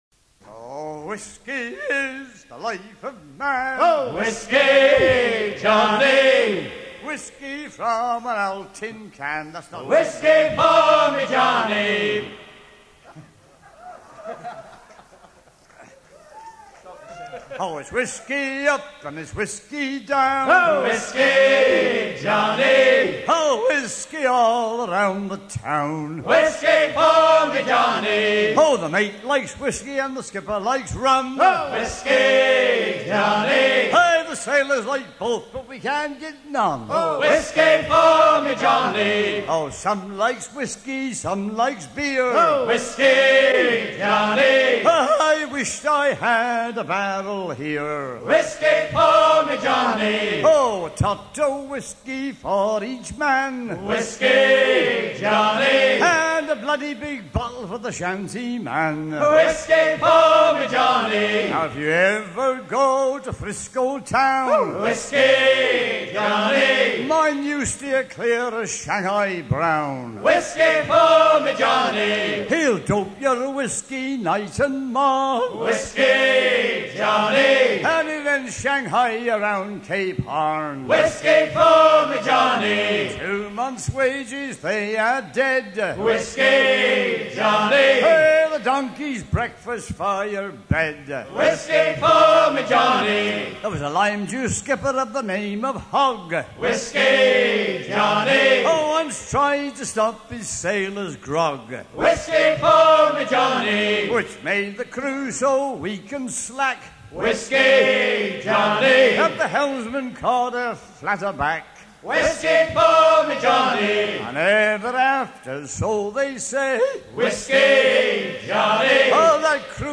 gestuel : à hisser main sur main
circonstance : maritimes
Pièce musicale éditée